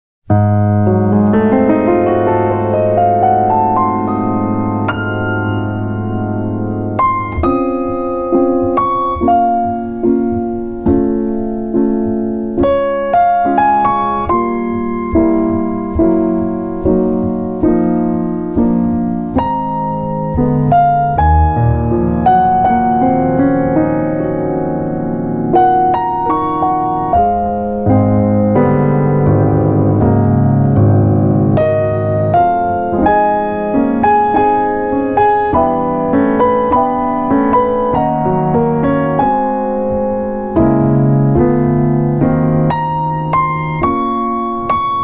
piano
bass
drums
Recorded at Avatar Studio in New York on April 26 & 27, 2010